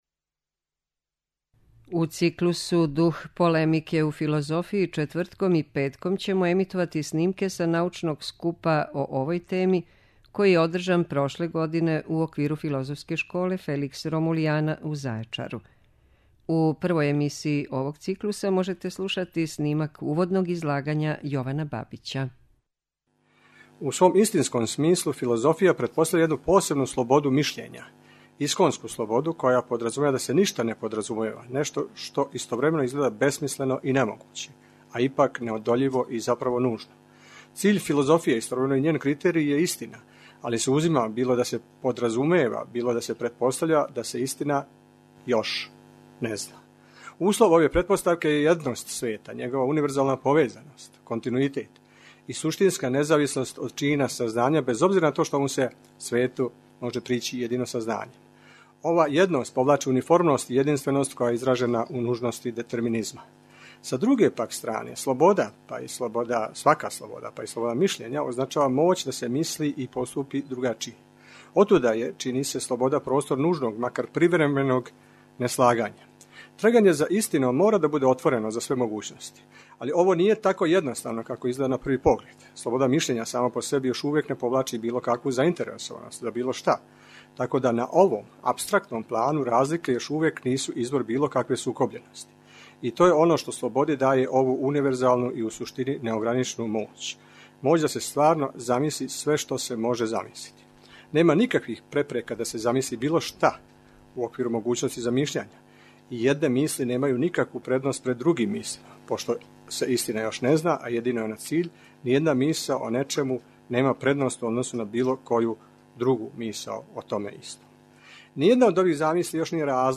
У циклусу ДУХ ПОЛЕМИКЕ У ФИЛОЗОФИЈИ четвртком и петком ћемо емитовати снимке са научног скупа о овој теми, који је одржан прошле године у оквиру Филозофске школе Феликс Ромулиана у Зајечару.
Научни скупови
Прошлог лета у Зајечару одржана је шеснаеста Филозофска школа Феликс Ромулиана, научни скуп са традицијом дугом 22 године.